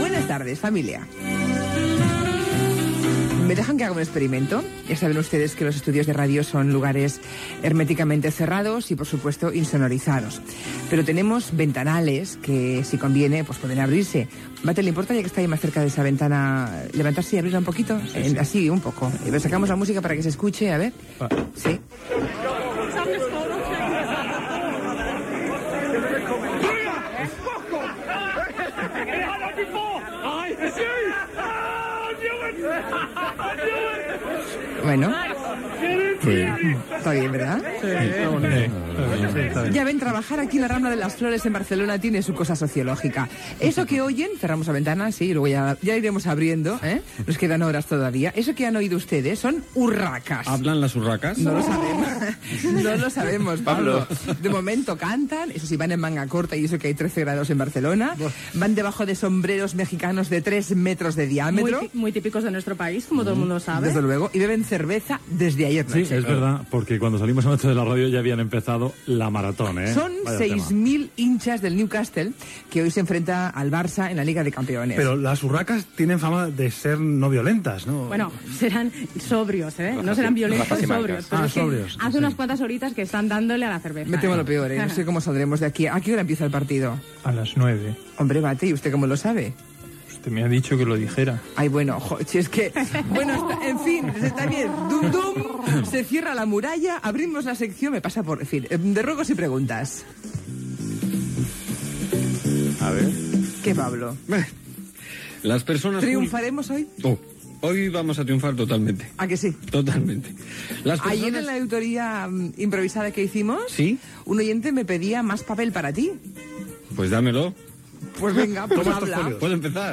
Diàleg sobre els aficionats del club de futbol New Casttle que estan pasejant per les Rambles de Barcelona.
Entreteniment